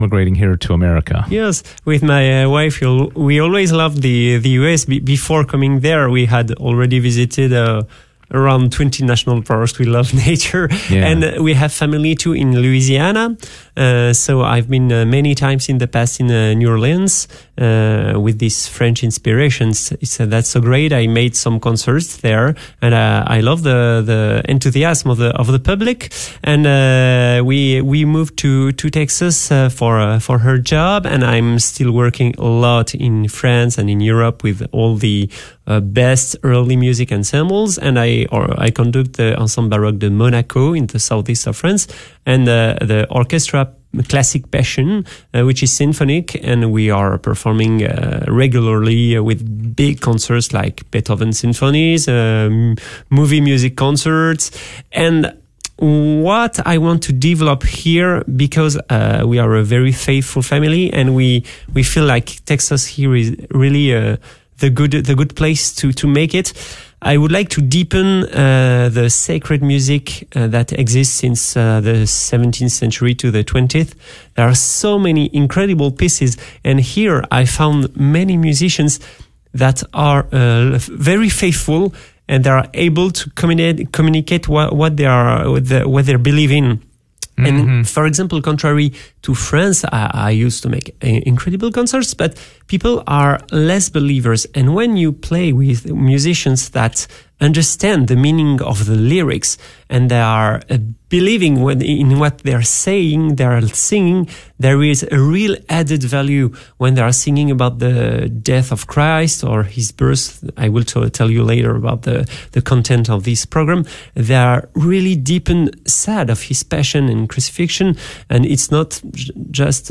KATH Interview of the Week
KATH Interview of the Week KATH Interview of the Week - Saturday October 12, 2024 Recorded on Saturday October 12, 2024 Share this episode on: KATH Interview of the Week The KATH Interviews of the Week air each Saturday during the 3pm hour on KATH 910 AM on the Guadalupe Radio Network. The interviews cover an eclectic blend of topics with the common bond that they all cover topics that are both Catholic and local in nature.